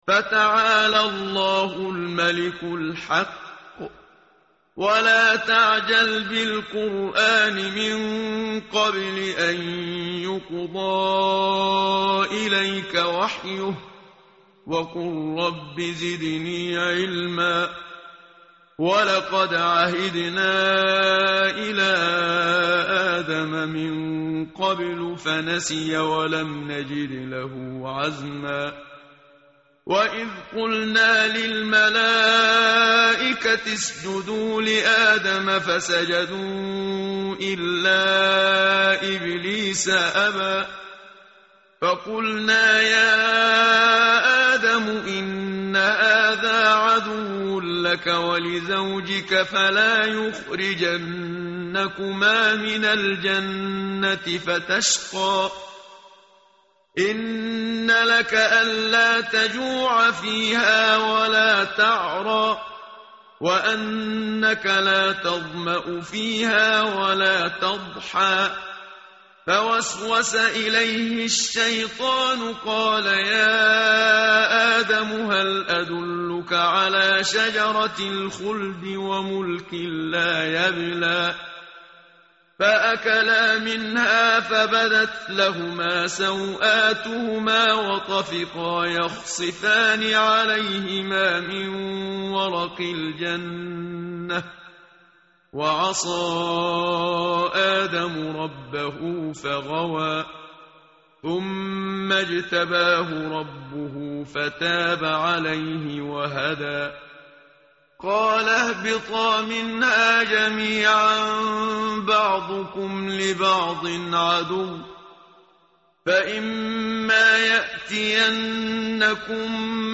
tartil_menshavi_page_320.mp3